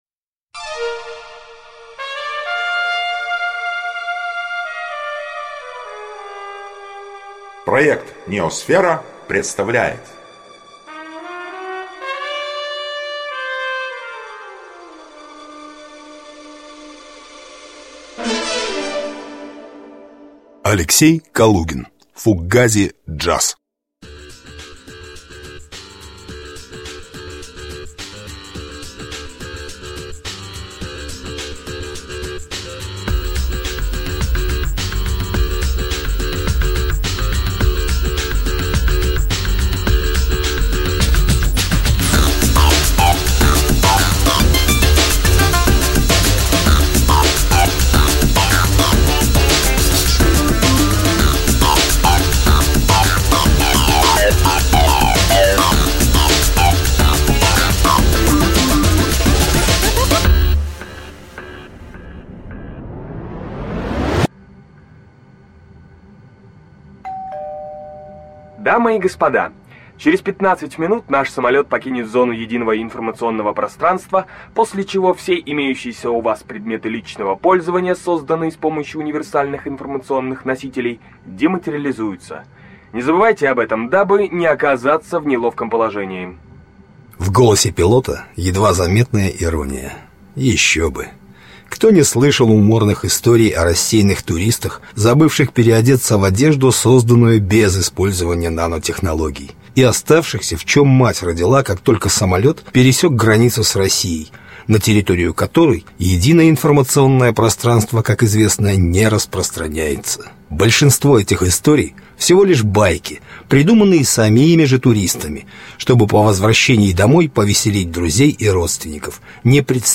Аудиокнига Фуггази-Джаз | Библиотека аудиокниг